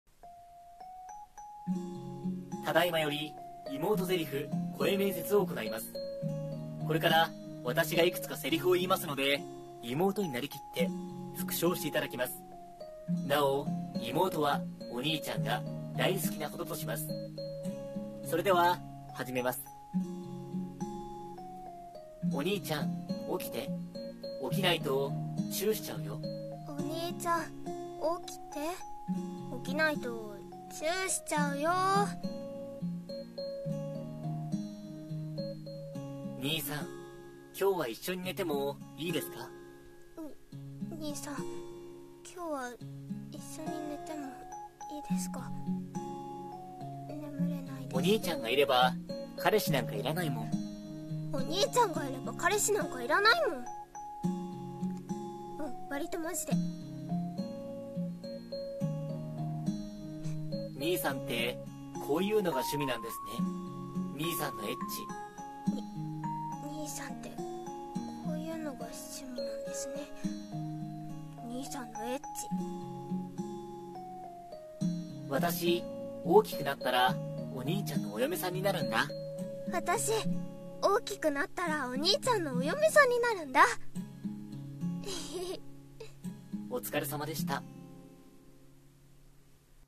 妹台詞声面接